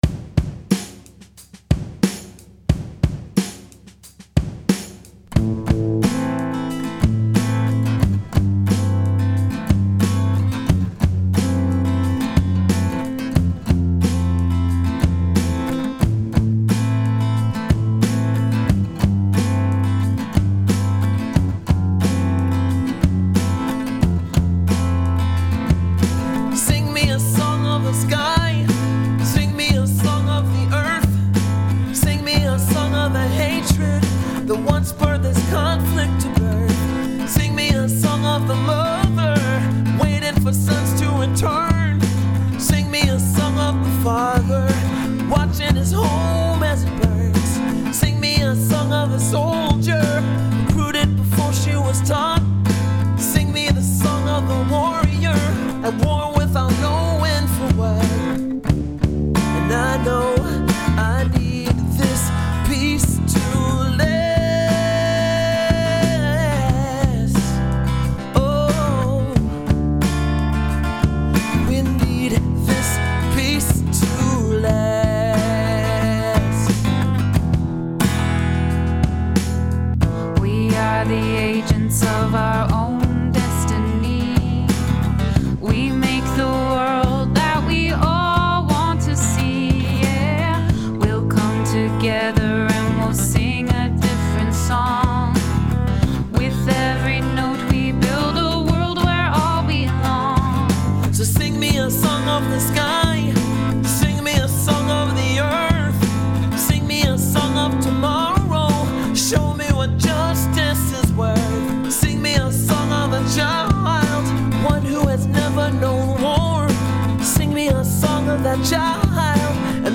Backing Track: